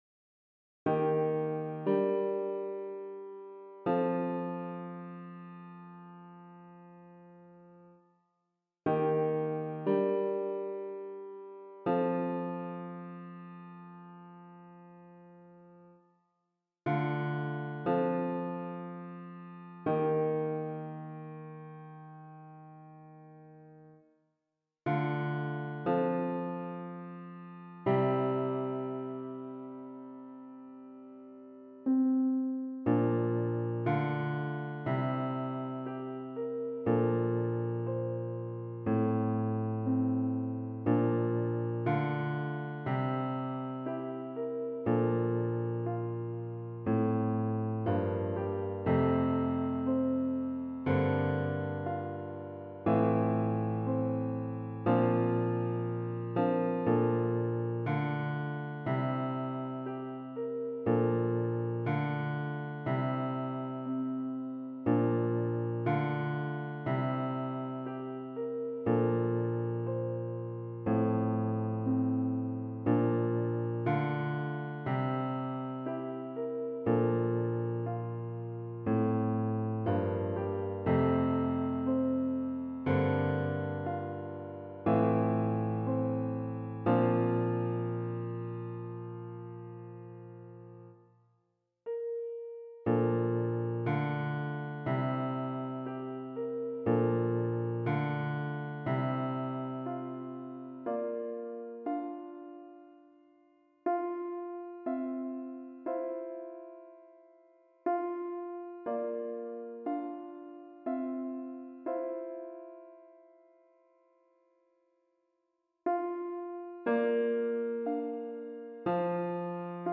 - Chant a capella à 4 voix mixtes SATB
Bass Piano